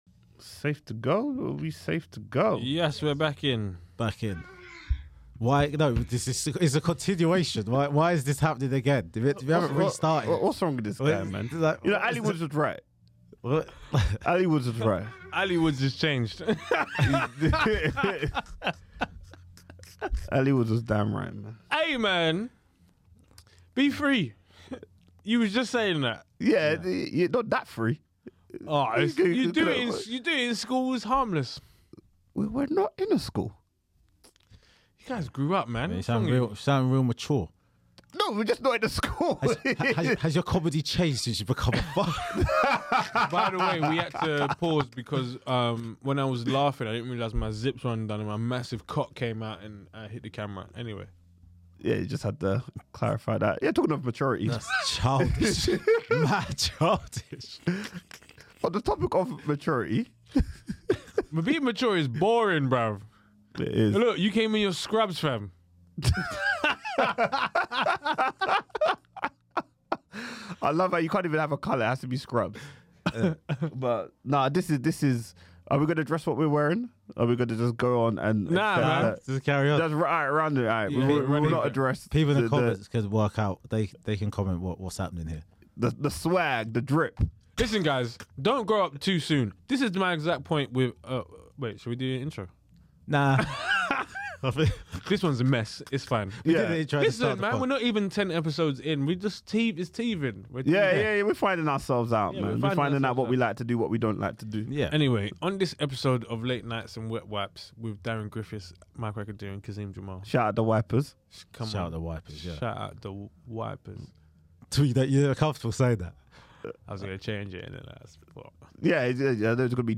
The three guys discuss changing behaviour for their kids.